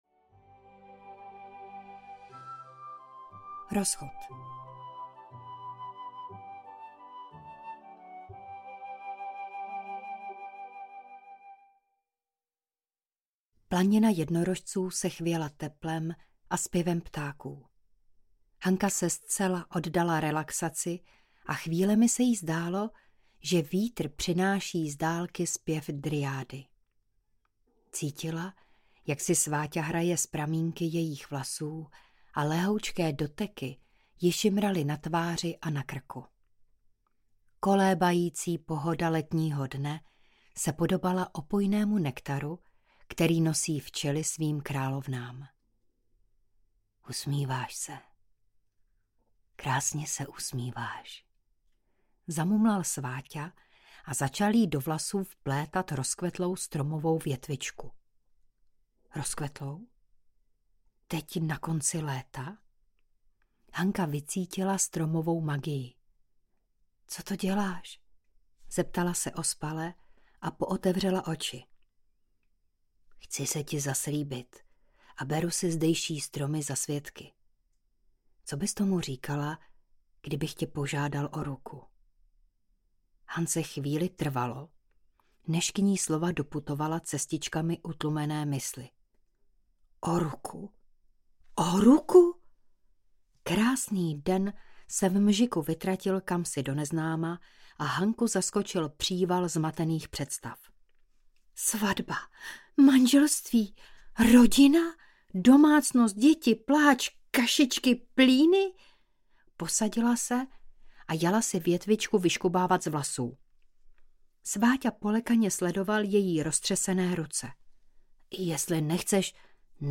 Naučte mě zabít draka audiokniha
Ukázka z knihy